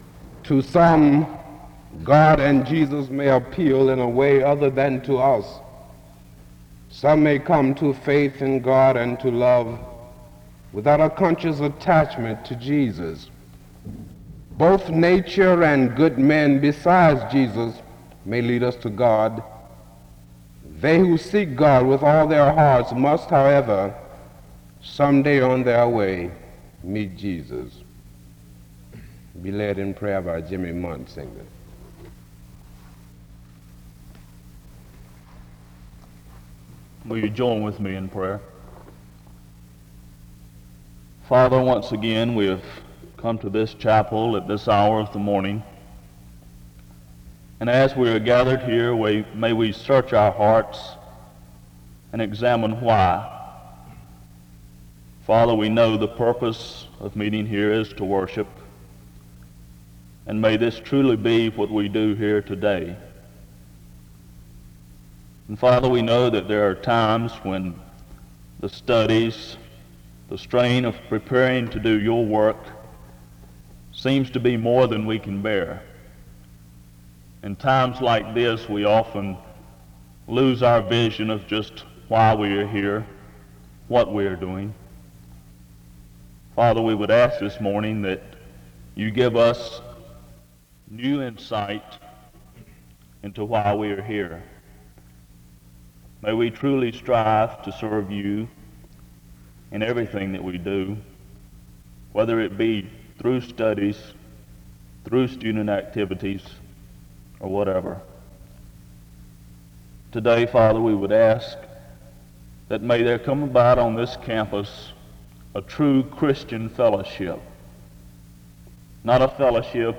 The service begins with a reading and prayer from 0:00-2:29. The order of the service is explained from 2:30-3:12.
This service was organized by the Student Coordinating Council.